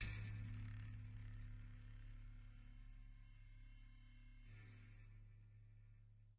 乐器是由金属弹簧从一个大的卡拉巴什壳上延伸出来的；录音是用一对土工话筒和一些KK;接触话筒录制的，混合成立体声。 动态用pp（软）到ff（大）表示；名称表示记录的动作。
Tag: 声学的 金属制品 冲击 弹簧 拉伸